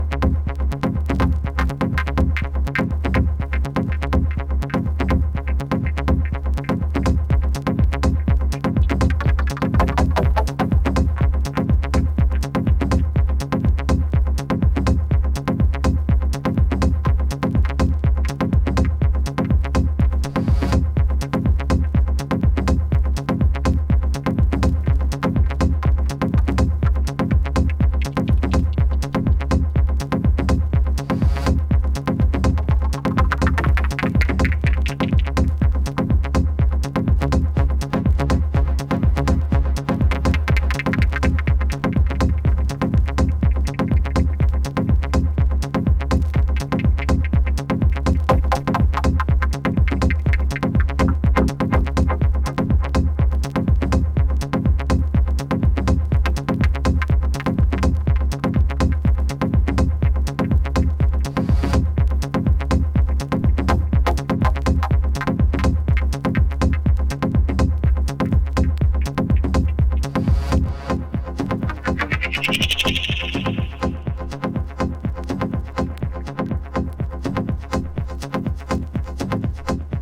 淡々とハメてくるウォーミーなDubby Tech A-2。